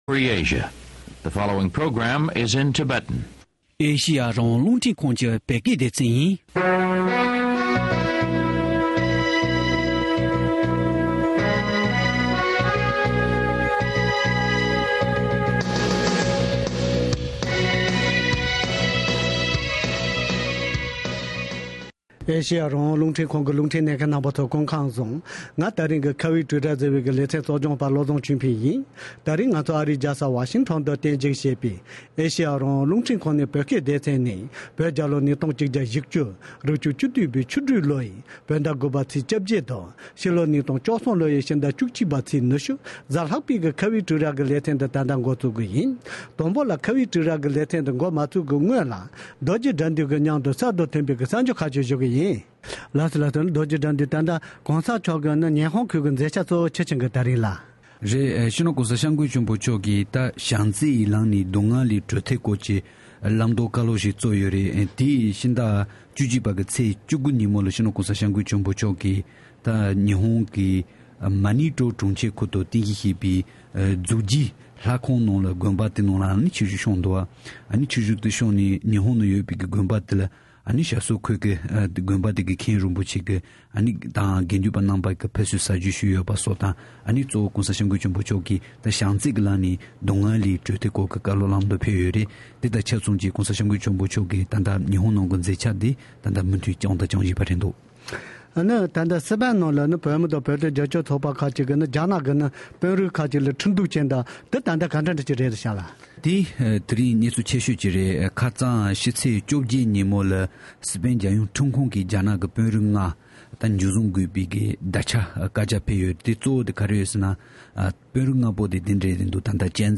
ཁོང་རྣམ་པ་གསུམ་དང་ལྷན་དུ་གླེང་མོལ་ཞུས་པ་དེ་གསན་རོགས་གནང་།།